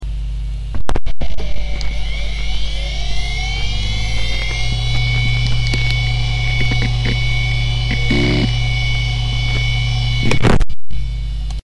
Все эти звуки были записаны с помощью японского контактного микрофона. К сожалению, полностью избавиться от фонового шума нам не удалось, да это и не надо: все звуки хорошо слышно, дополнительной очистки наши записи не требуют.
Звук нормально работающего накопителя Quantum Plus AS: